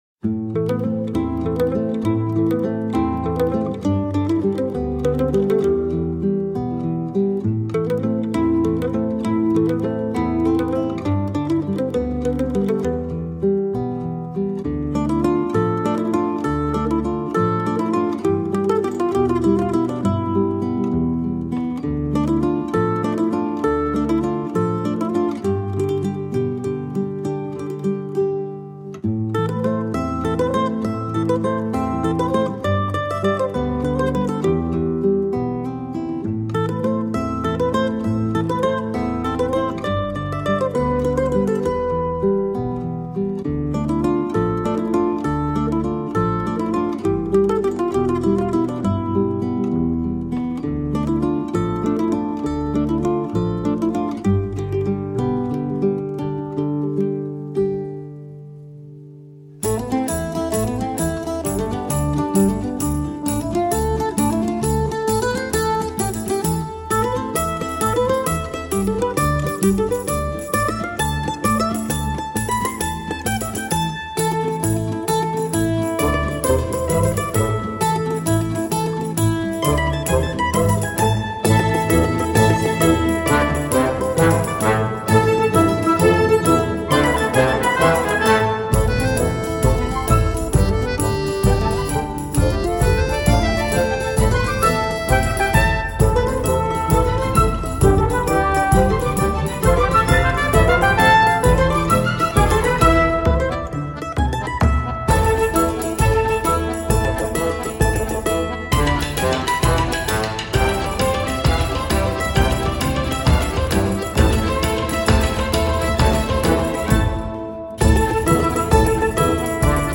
Rafraîchissant.
orchestrale, dominée par la guitare sèche et le piano